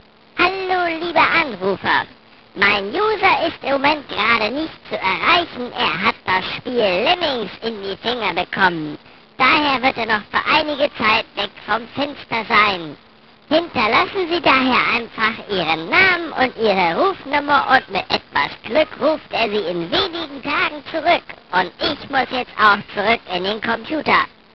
A small, funny text for your telephone answering machine.